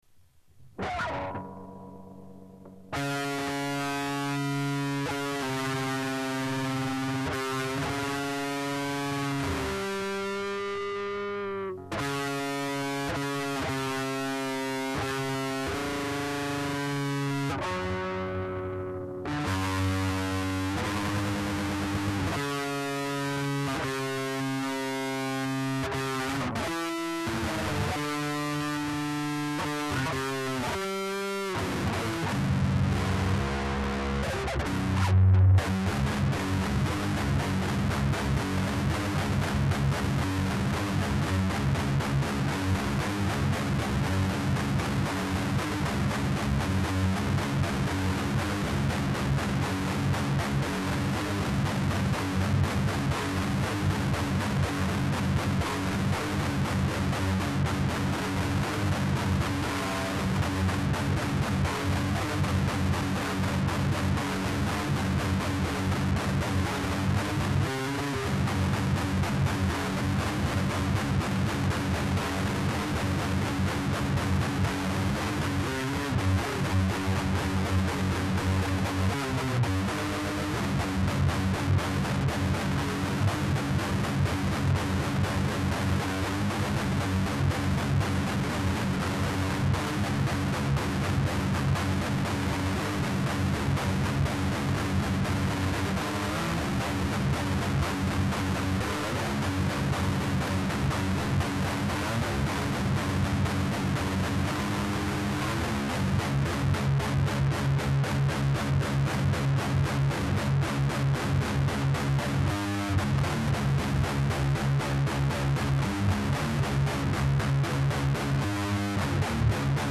séances impros guitare du soir
en 2020 j'ai pris la guitare, une guitare de gaucher retournée depuis elle a été révisée et j'ai changé les cordes pour du gros (0.52) je sais toujours pas jouer, juste de la rythmique et du powercord
pour ne pas arranger les choses j'ai du matos de crotte, je joue sur ma hi-fi pas glorieuse.
2_pas de résonance, solo qui tombent à plat